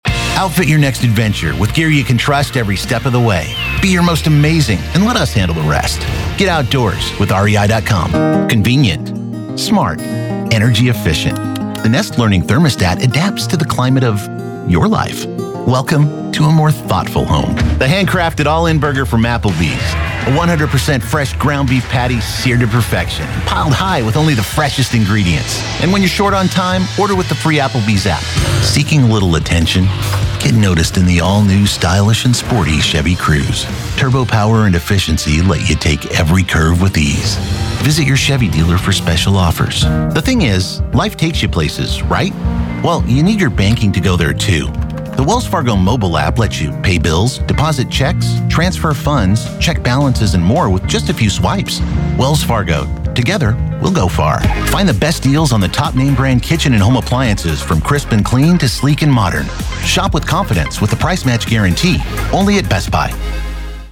Commercial - US
All vocals are recorded in a professional studio with state of the art equipment including Sennheiser MKH-416, Avalon 737 preamp, and Adobe Audition DAW.